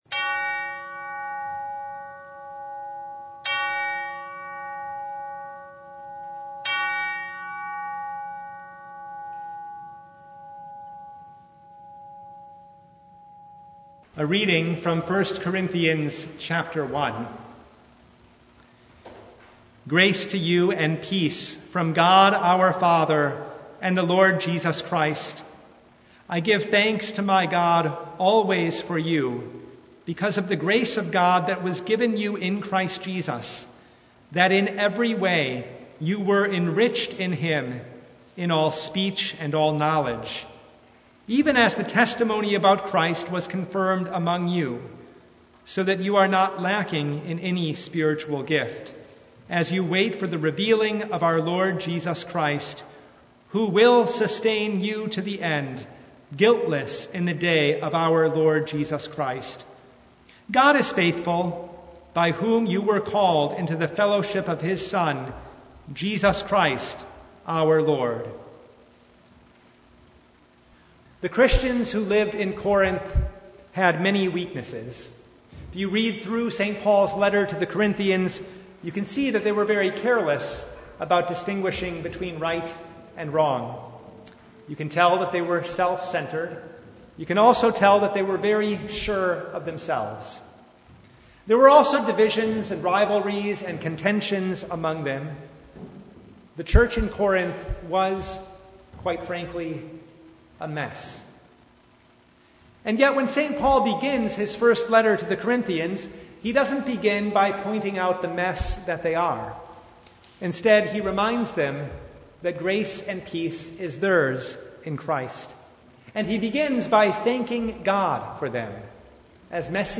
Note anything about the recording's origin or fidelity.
Service Type: Advent Vespers